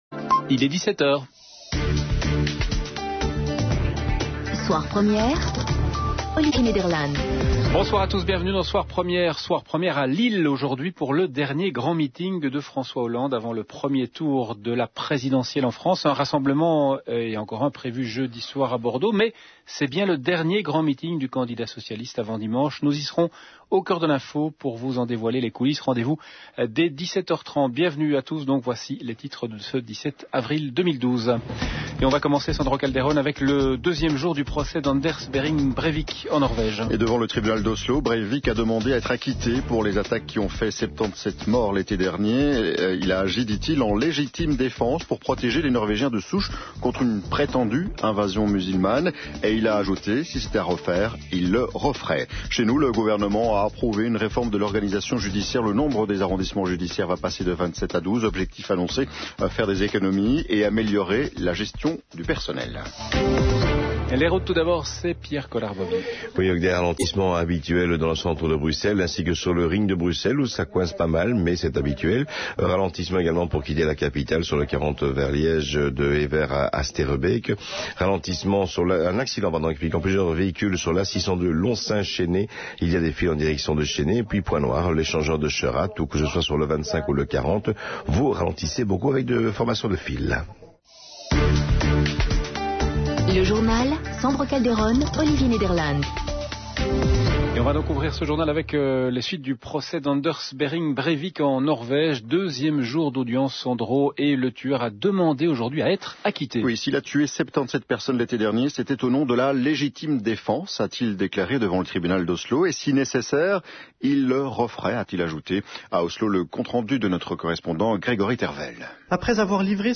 Reportage La Première P-Day